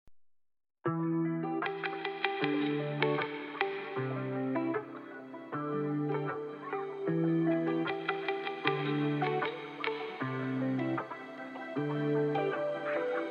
כמובן שיש על זה הרבה מניפולציות (המון ריוורב, EQ, ועוד).